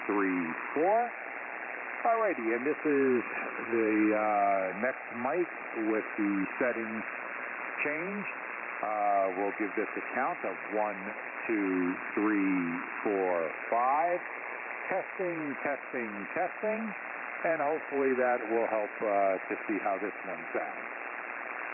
All of the tests were conducted in the HF bands including 80, 40 and 10 meters.
• Sounds fuller with EQ off
• Sounds fuller than the other mics
• Lows are stronger than highs
Electro Voice RE-320 EQ Off
EV-RE-320-EQ-Off.mp3